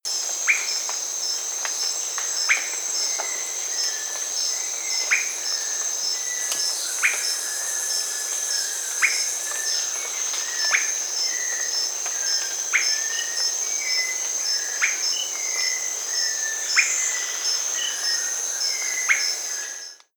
Swainson’s Thrush
The Swainson’s thrush makes one of my favourite calls, commonly heard in late spring and early summer as the sun is setting and the air is cooling (although you do hear the same call during the day, it’s competing with far more bird calls for your attention—at dusk, they stand alone).